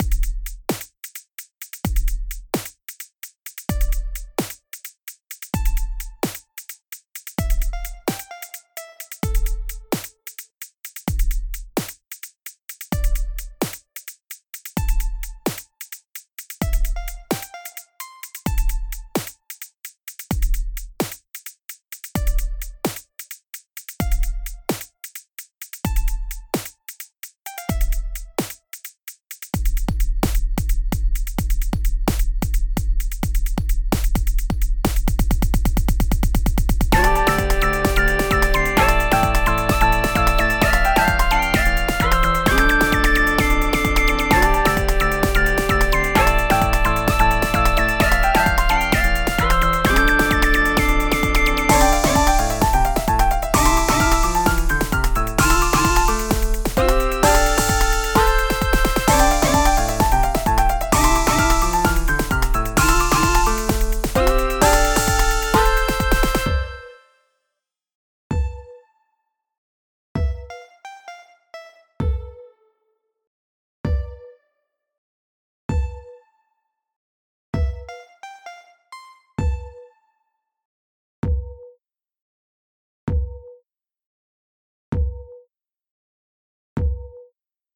ドラムスの低音がいいっすね！
40秒からの展開がかっこええ！